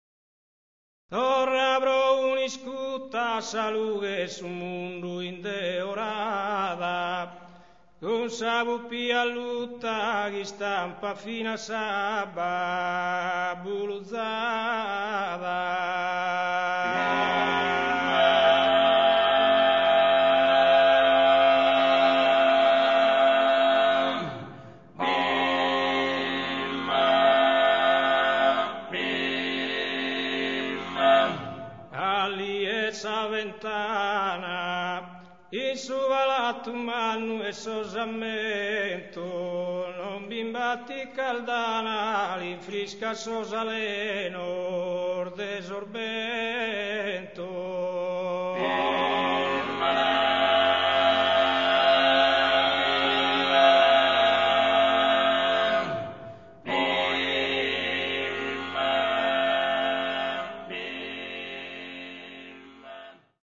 Il 1976 vede il battesimo del palco di questo gruppo formato da quattro adolescenti, da anni gi� appassionati di canto a tenores.